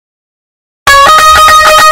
Loud Indian Music Short
Loud-Indian-music-short.mp3